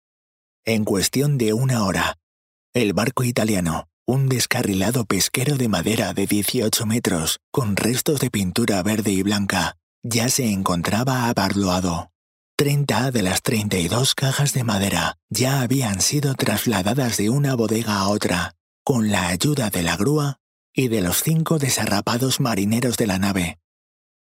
Locutor profesional en Español con estudio de grabación y disponibilidad 24/7.
Sprechprobe: Sonstiges (Muttersprache):
Professional spanish voice over specialised in radio ads and corporate messages.